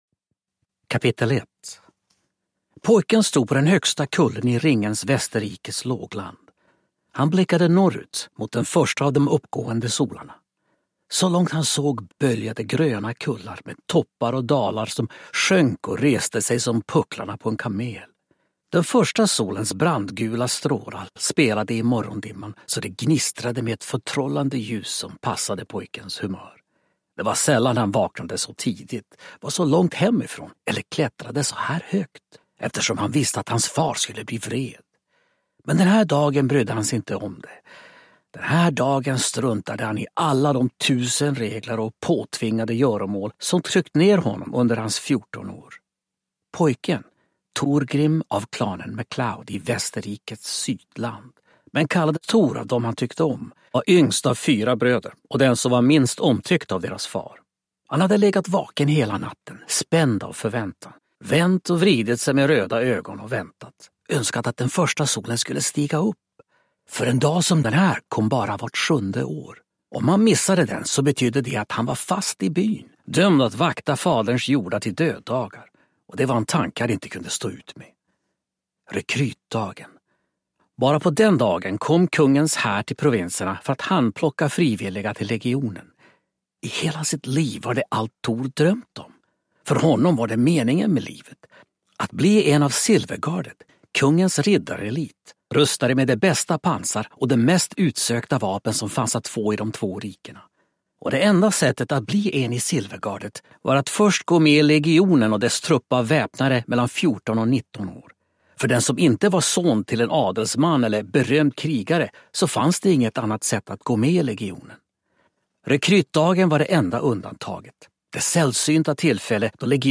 Аудиокнига Hjältars Väg | Библиотека аудиокниг